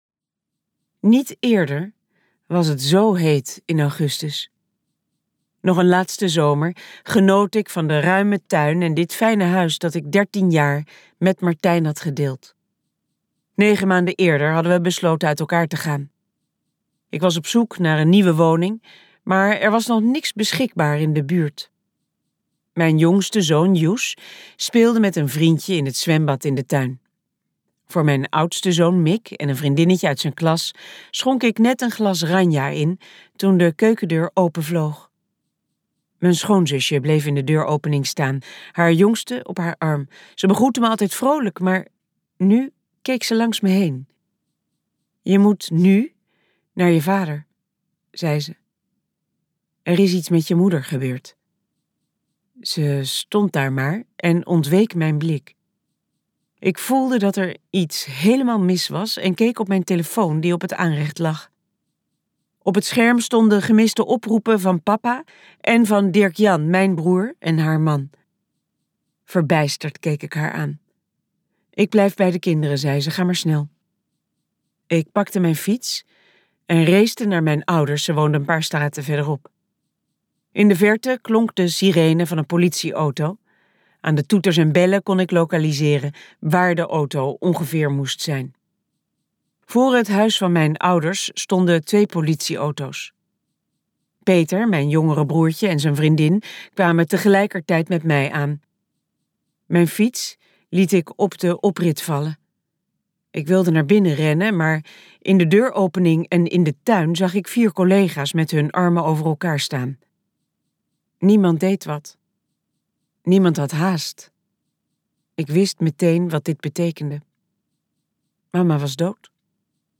Ambo|Anthos uitgevers - Achter het afzetlint luisterboek